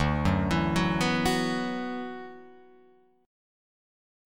D6add9 chord